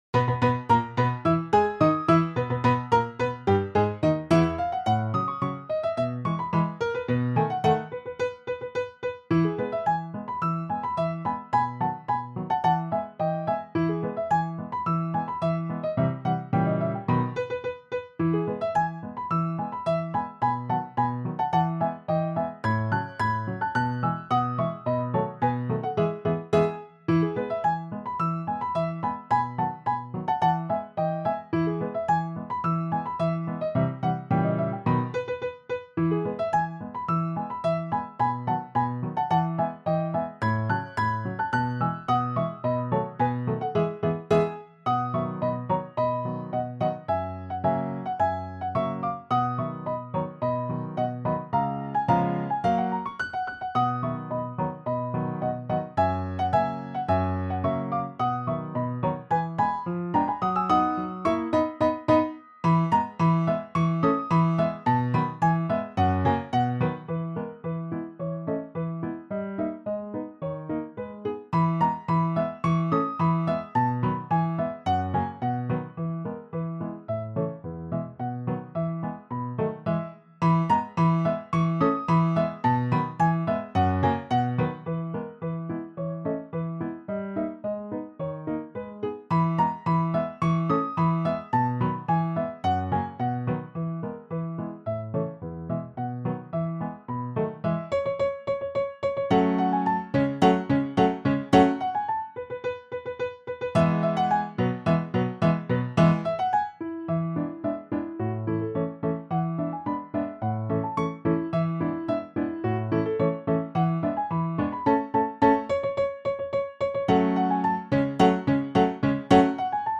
Augusta-Polka-SB-piano-track-3.m4a